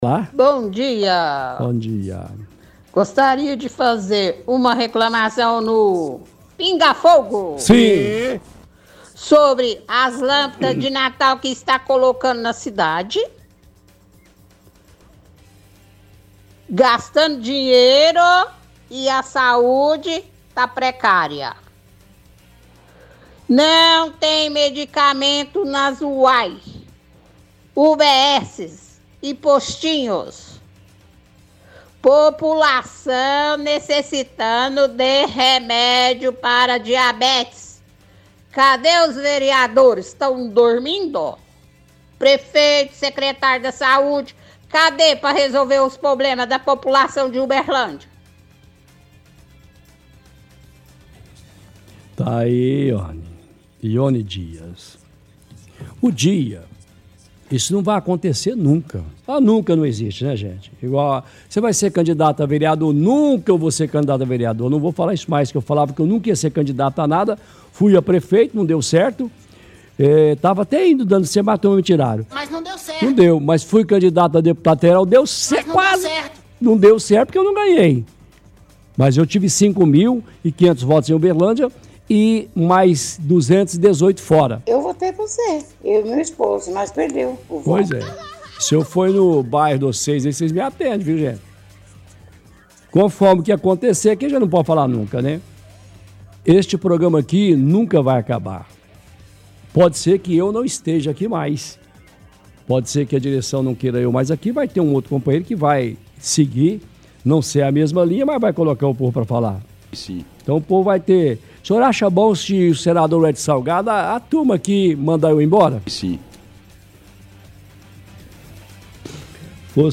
– Ouvinte reclama das decorações de natal que está sendo colocada na cidade, fala que está gastando dinheiro do povo, Cita que a saúde está precária. Fala que UBS não tem medicamentos para diabéticos.